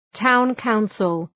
Προφορά
{,taʋn’kaʋnsəl}